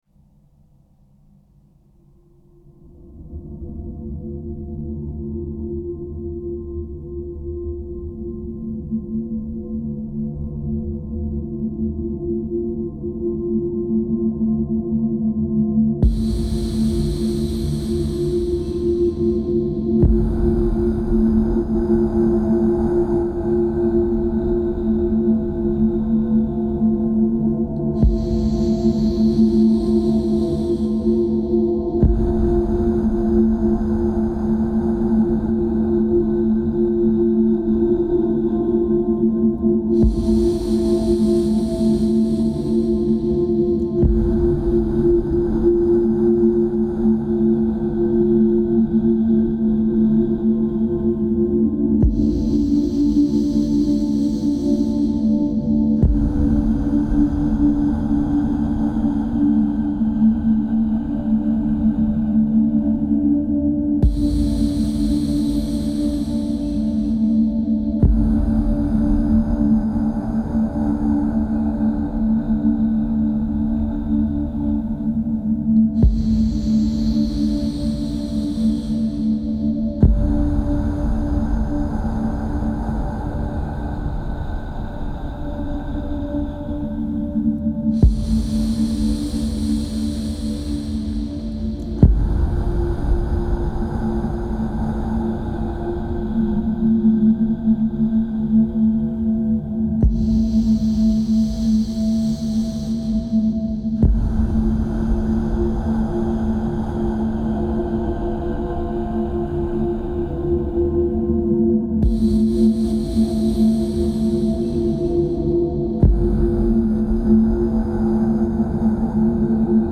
This is a track that follows the structure of the original Daily Dose, however, the breathing pattern is on 4:8 for the firs 11 mins before the first breath retention.